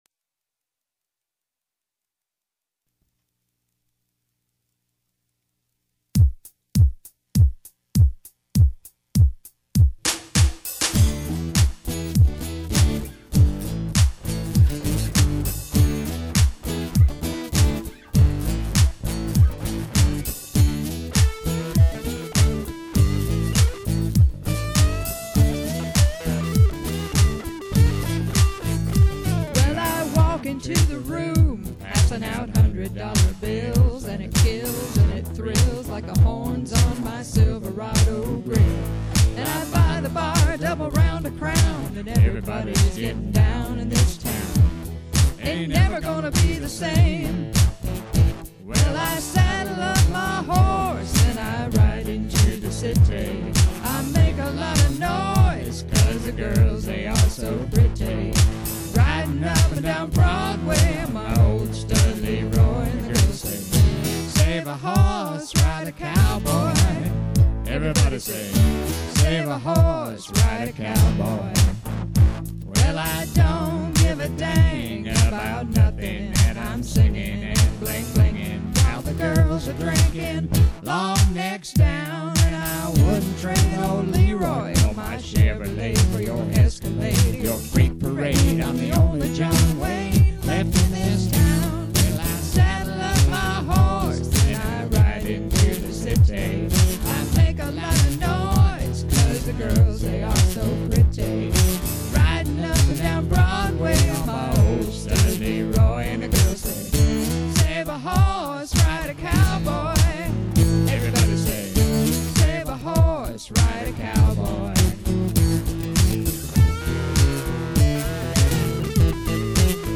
This is the ultimate variety band.